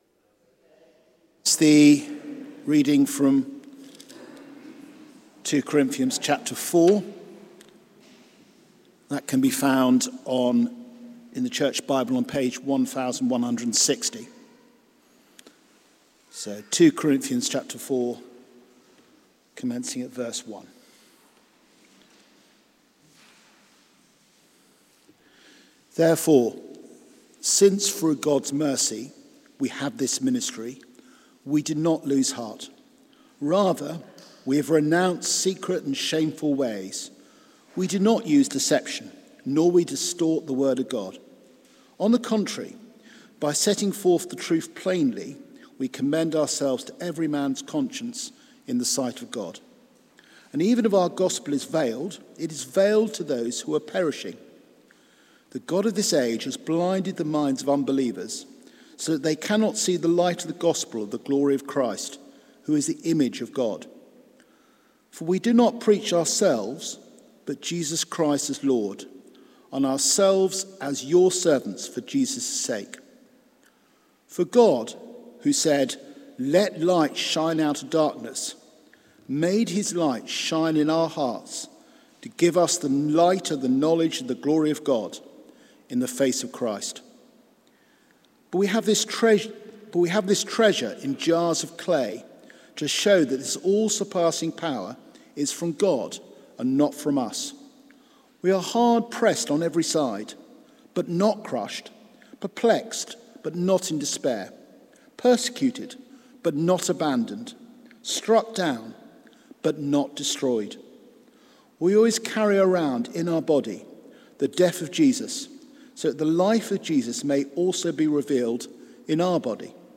Integrity in Action Theme: Keep Going Sermon